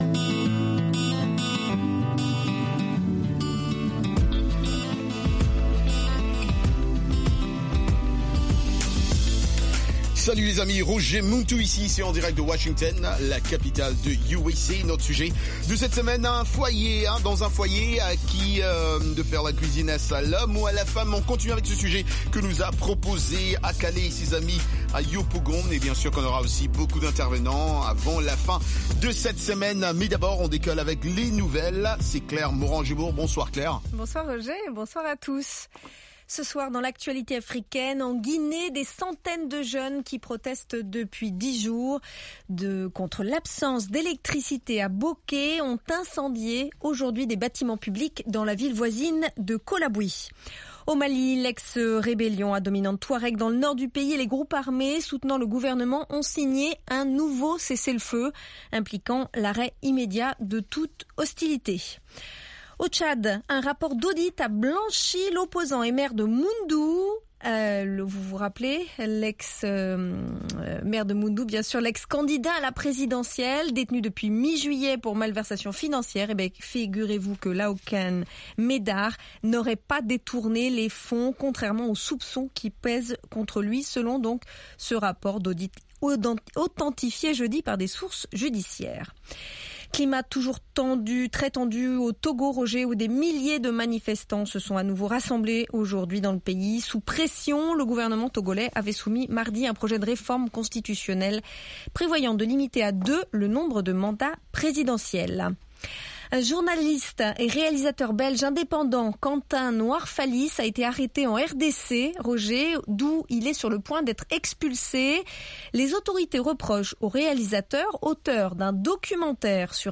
RM Show -Afro Music et RM Afro Hit-Parade